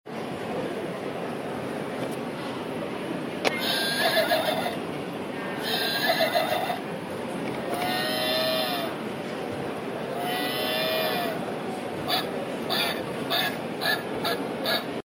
Farm sound puzzles to hear sound effects free download
Farm sound puzzles to hear how the animals make sound.